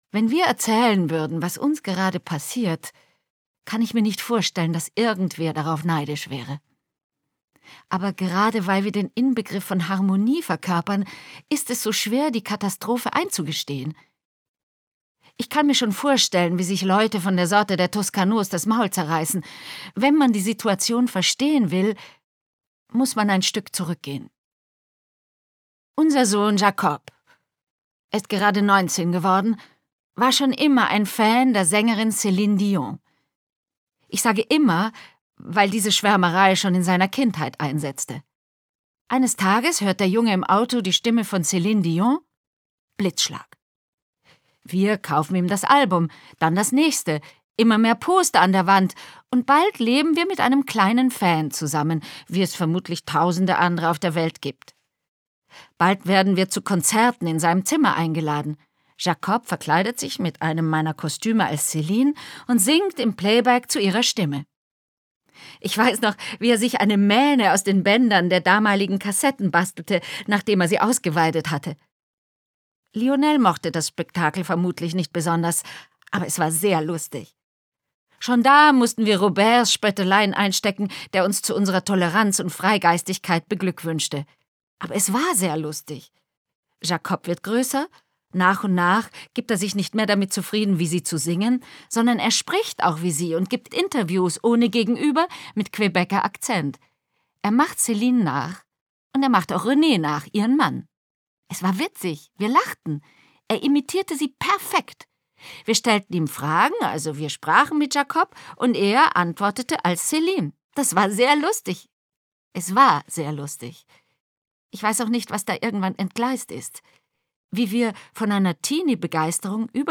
Matthias Brandt , Wolfram Koch , Eva Mattes , Birgit Minichmayr , diverse (Sprecher)